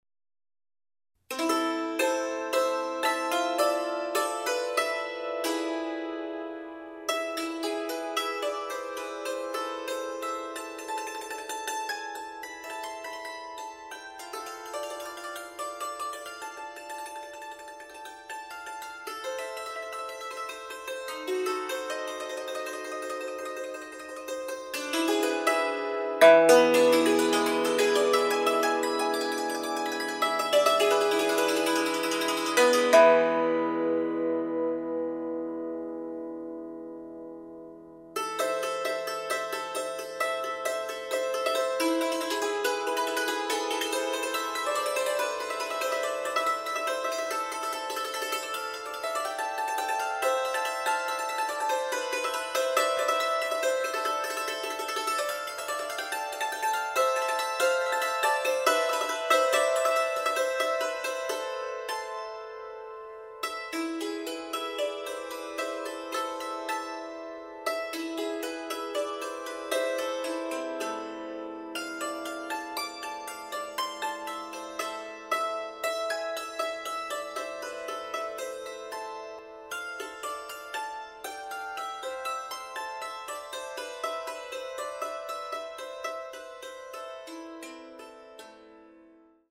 Dulcimer Artist and Story Teller
Russian and Ukrainian folk tunes